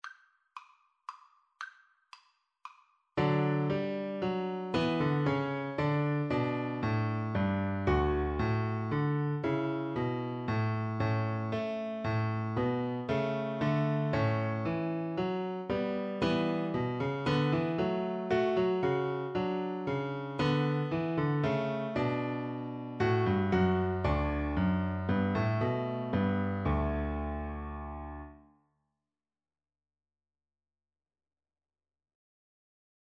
Free Sheet music for Piano Four Hands (Piano Duet)
D major (Sounding Pitch) (View more D major Music for Piano Duet )
3/4 (View more 3/4 Music)
Traditional (View more Traditional Piano Duet Music)